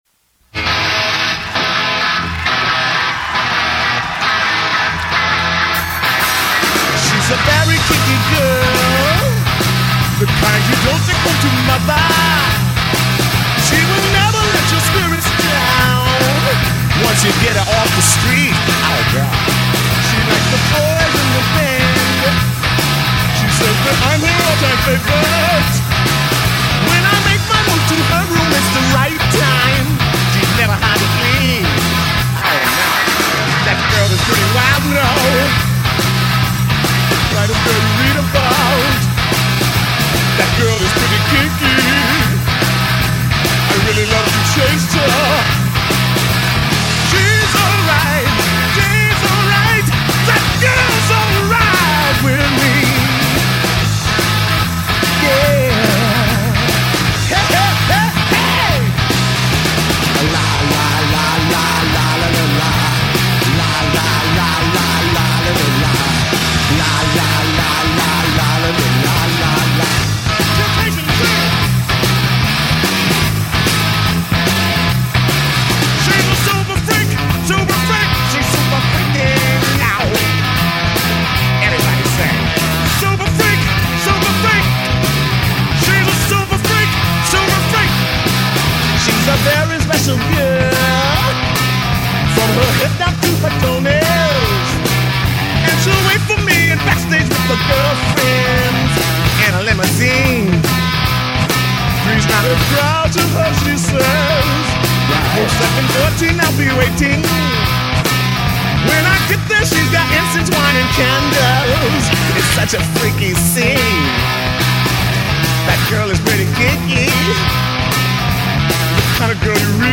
Instrumentale
acapella .